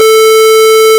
Descarga de Sonidos mp3 Gratis: tono 3.